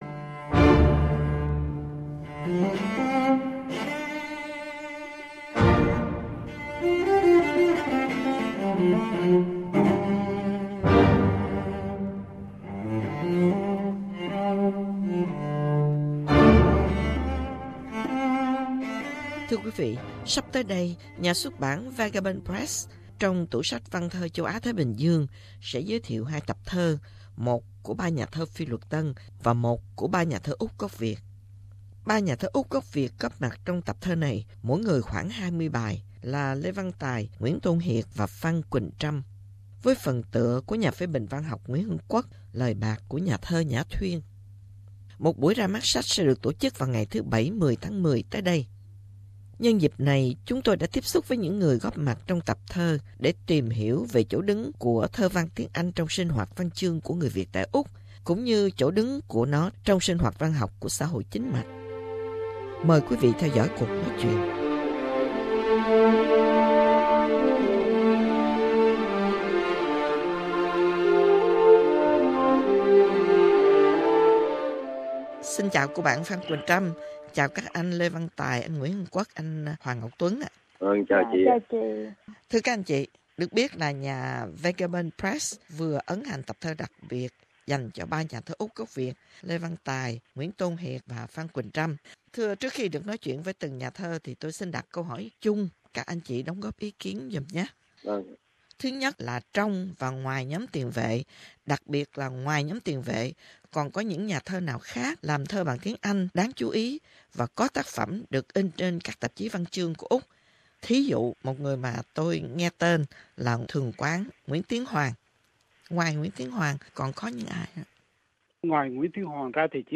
Phỏng vấn trên đài SBS